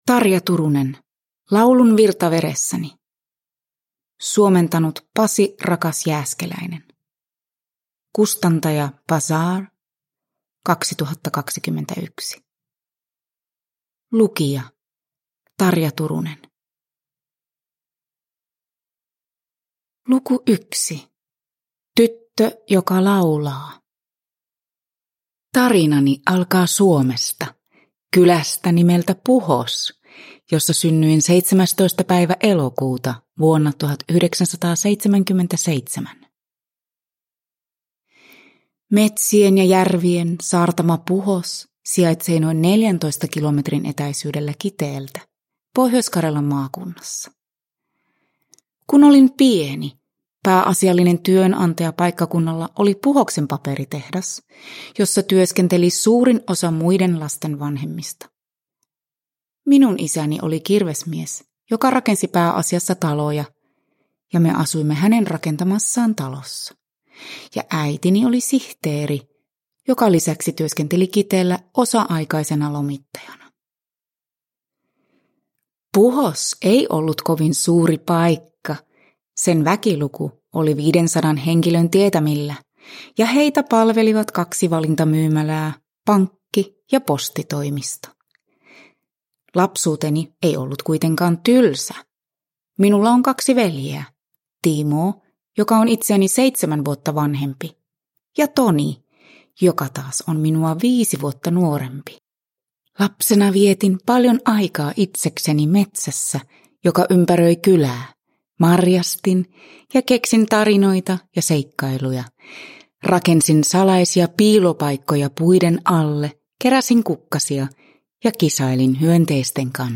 Laulun virta veressäni – Ljudbok – Laddas ner
Uppläsare: Tarja Turunen